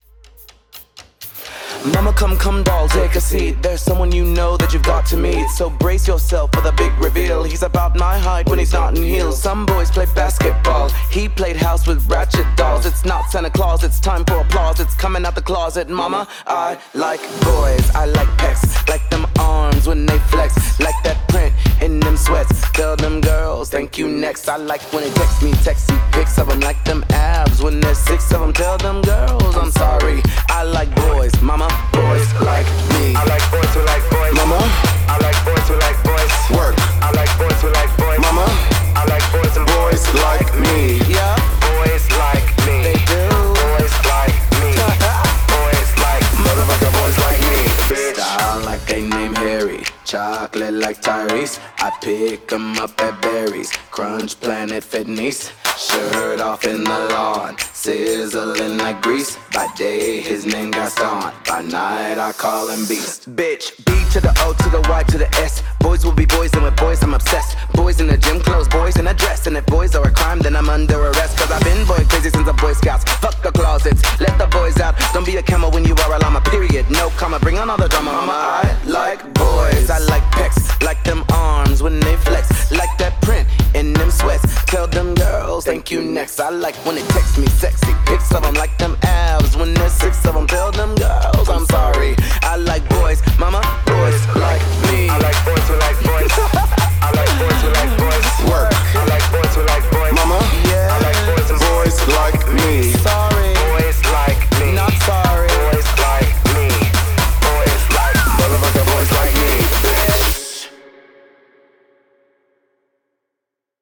BPM124
Audio QualityCut From Video